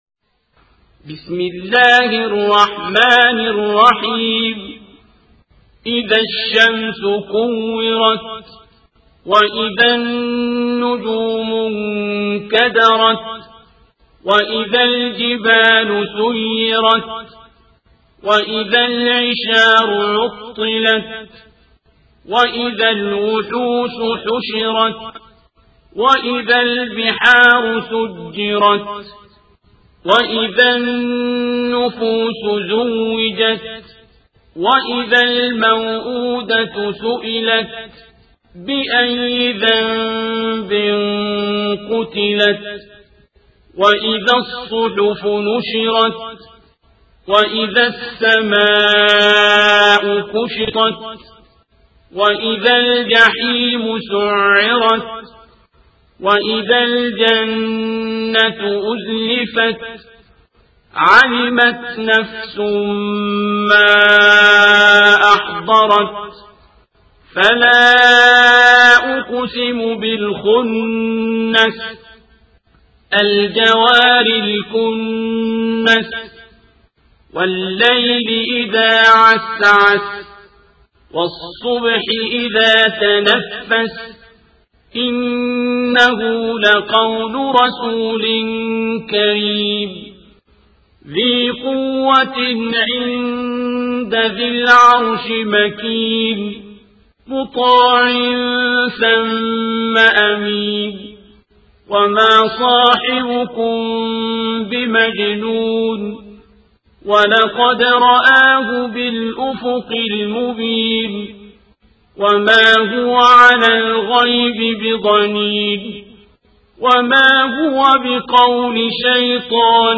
القارئ: الشيخ عبدالباسط عبدالصمد